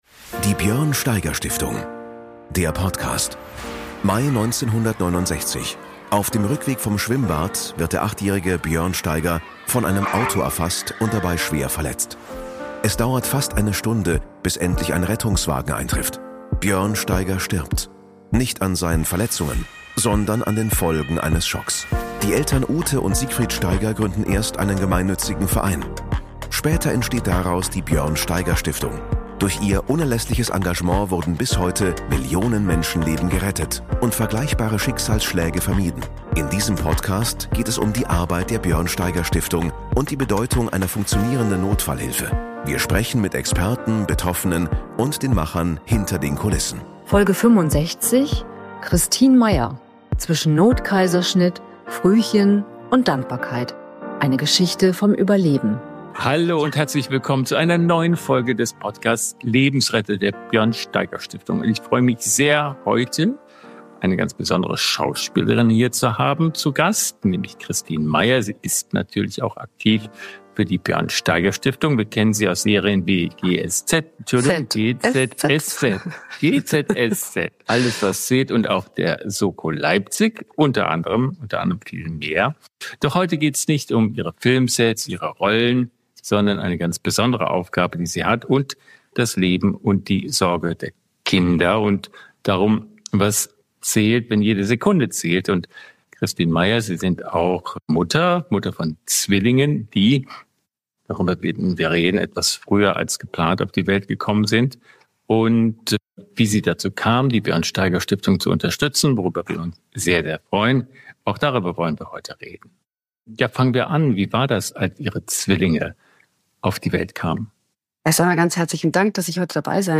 Zu Gast ist die Schauspielerin Kristin Meyer.
Mit Béla Anda spricht sie über eine sehr persönliche Geschichte: Kristin Meyer ist Mutter von Zwillingen – Zwillingen, die zehn Wochen zu früh auf die Welt kamen.